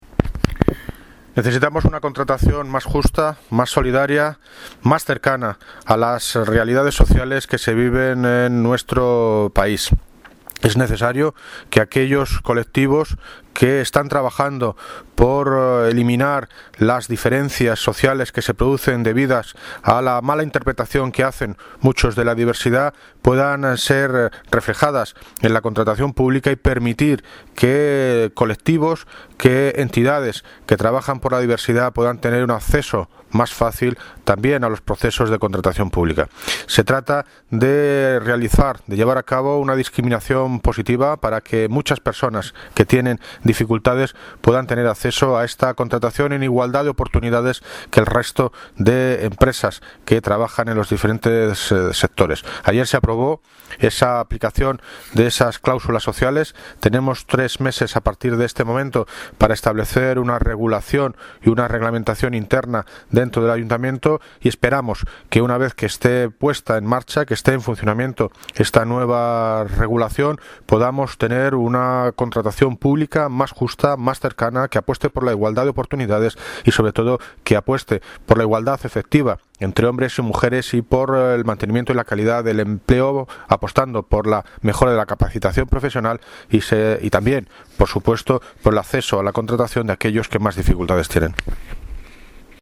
Audio - David Lucas (Alcalde de Móstoles) Sobre Cáusulas Sociales